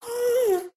moan3.ogg